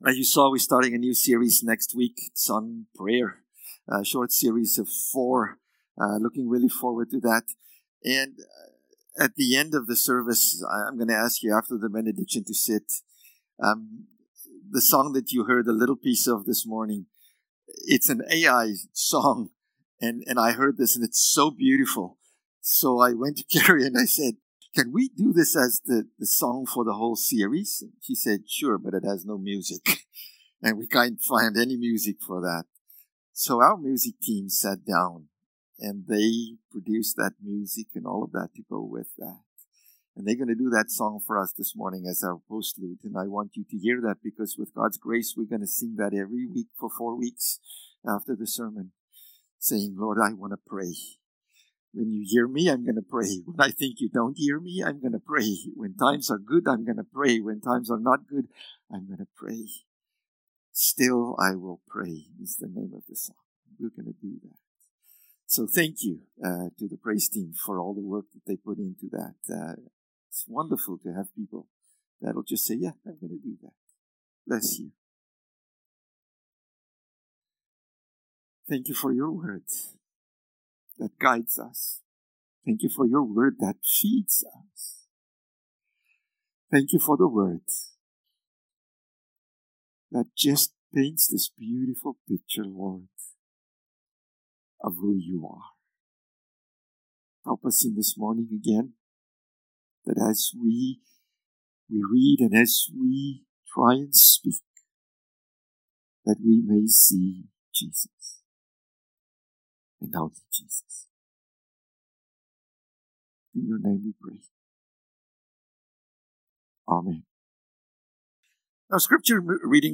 April-19-Sermon.mp3